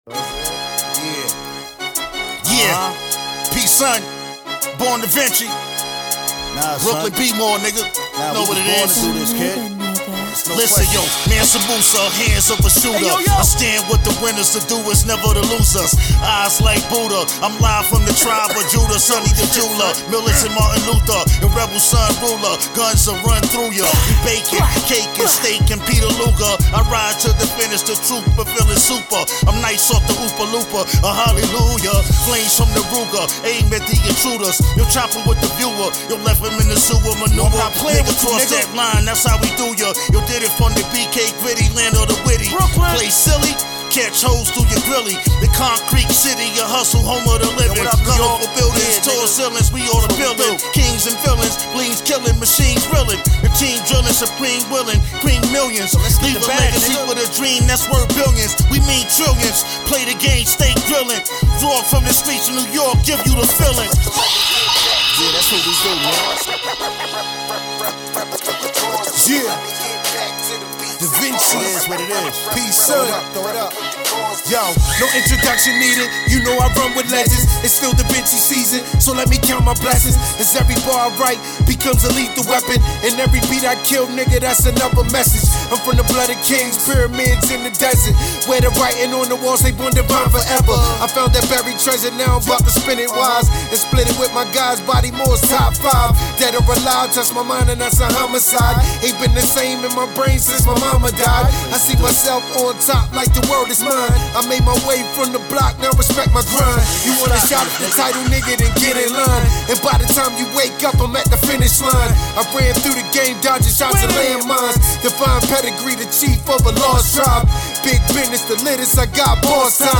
SPITS THE TRUTH WITH REAL LIFE EXPERIENCE